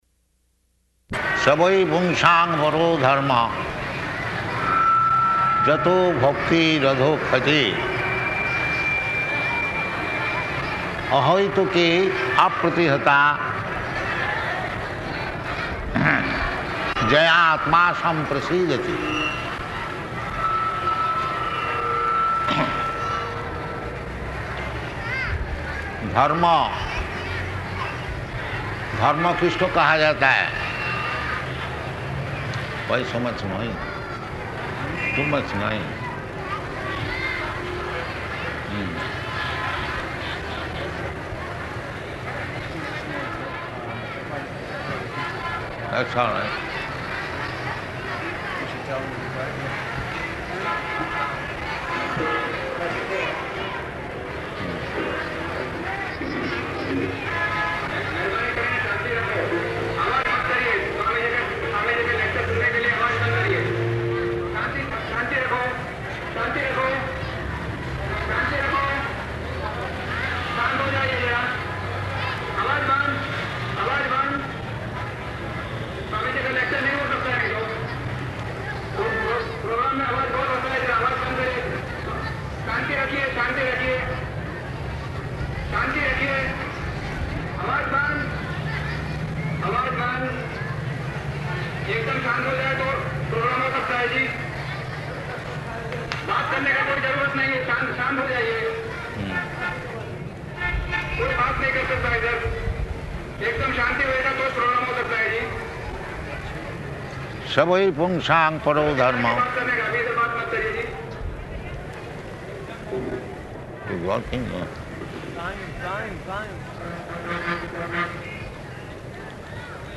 Location: Ahmedabad
Why so much noise? Too much noise!